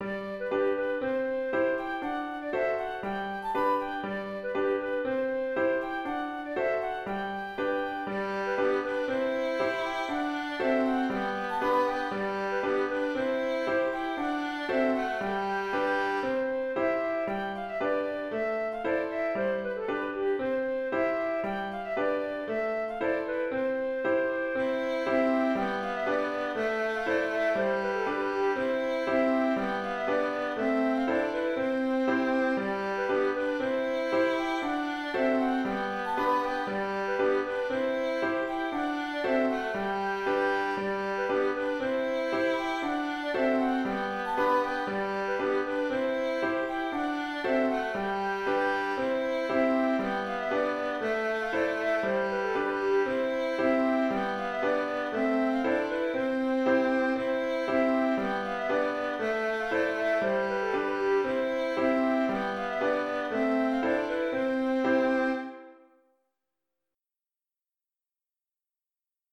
Bourrée de la Brande d'Ardantes (Bourrée en cercle) - Musique folk
C'est un traditionnel du centre de la France, et plus précisément du Berry, pays qui inspira le roman de Georges Sand, "Les Maîtres Sonneurs".